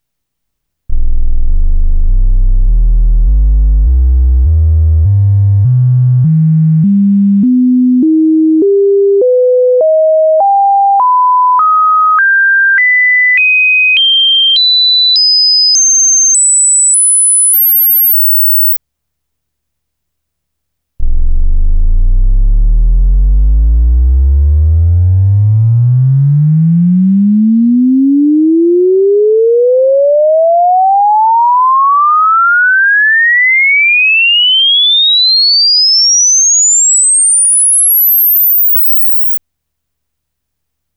これをそれぞれのプレイヤーで再生し、それを録音しました。録音環境は、以下の通り。
これをプレイヤー→ミキサー→Delta66と繋げて録音しました。
一方、中国製プレイヤーは低周波域でふくらんでいます。
音も明らかに違います(44.1kHz 16bit wav)
中国製プレイヤーの音の悪さはこの波形歪みが原因の一つのようです。前から、妙に低域がふくらんで聞こえたのもスペクトルを見ることではっきりしました。